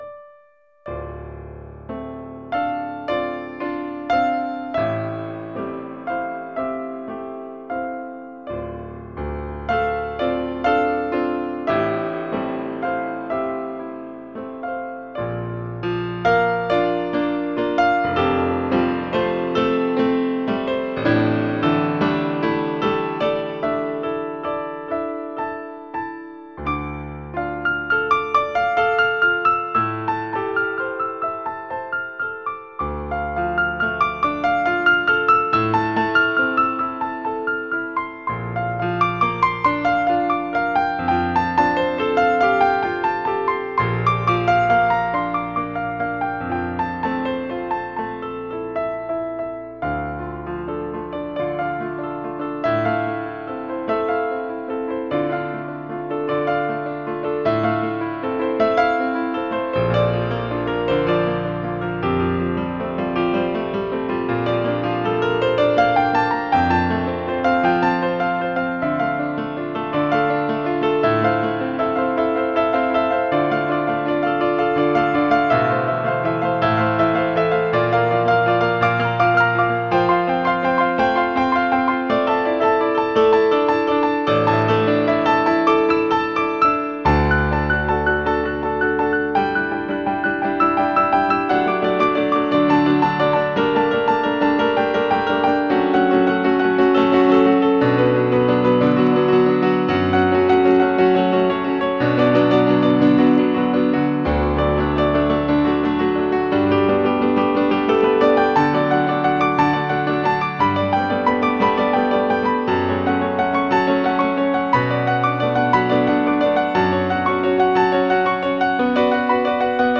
Stage of Contact improvisation, February 7th: improvisation.